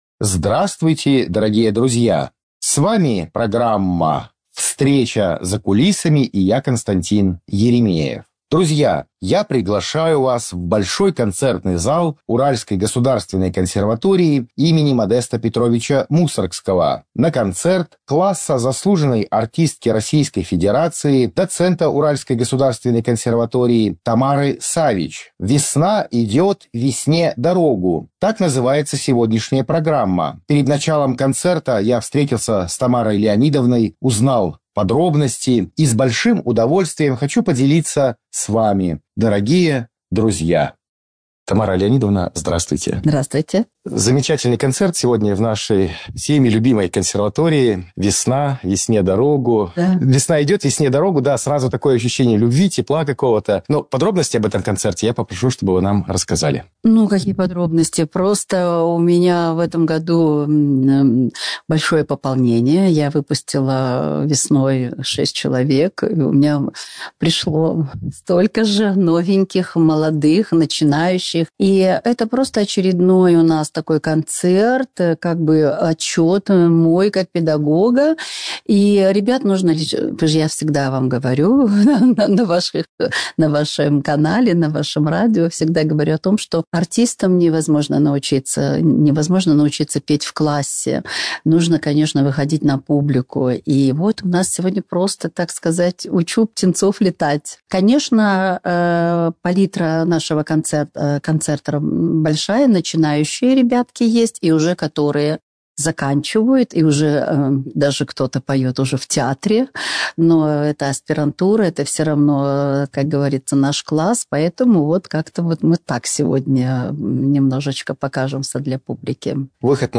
Концерт "Весна идёт, весне дорогу"